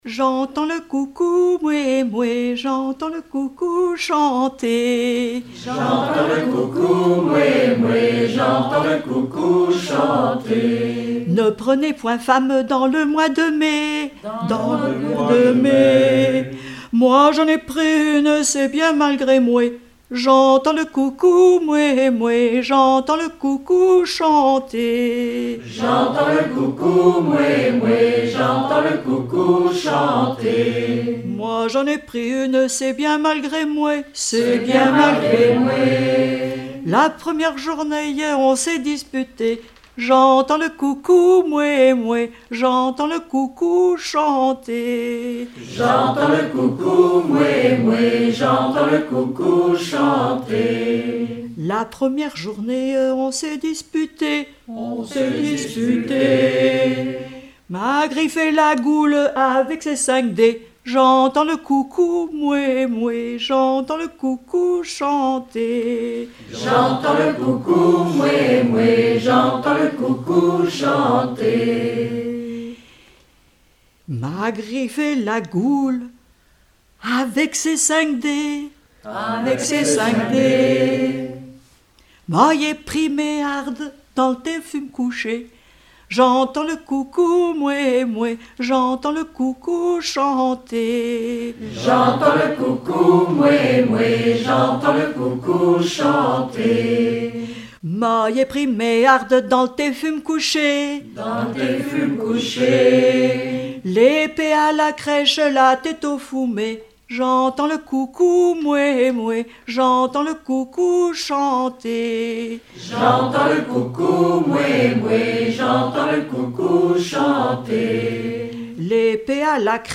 Collectif de chanteurs du canton - veillée (2ème prise de son)
Pièce musicale inédite